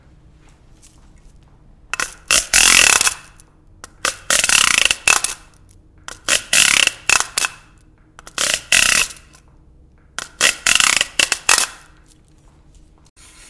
描述：由木材对金属的刮擦而形成。